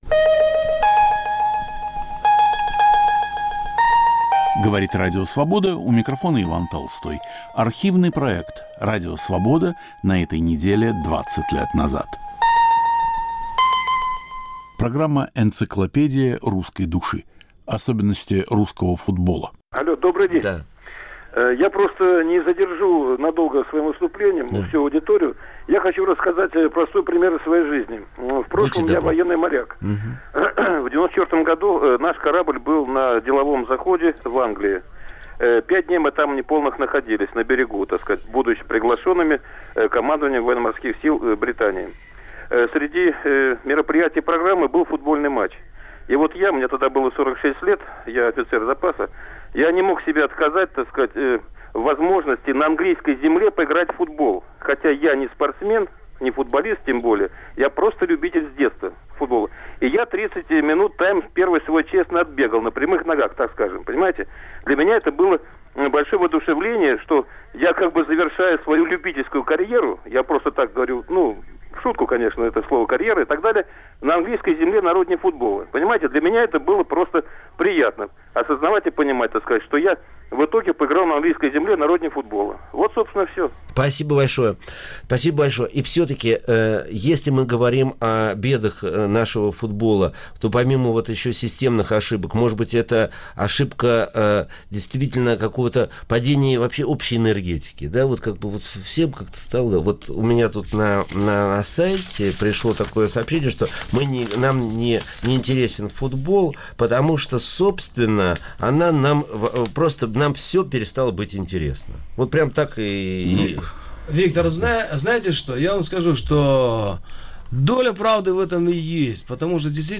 Почему невозможно собрать хорошую команду. В студии Радио Свобода писатель Аркадий Арканов и поэт Александр Ткаченко. Передачу подготовил и ведет Виктор Ерофеев.